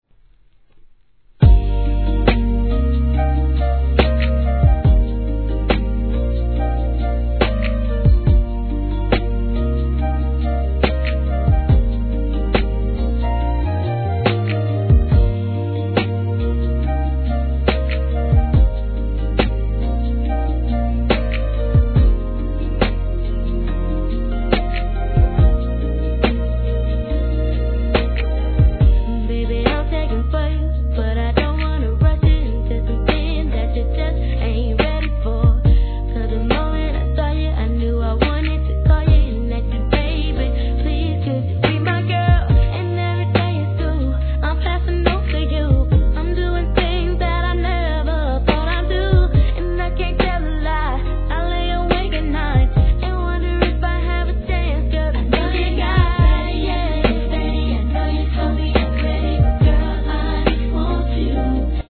HIP HOP/R&B
本作も透き通るようなキャッチーなヴォーカルが胸キュン系で万人受け!!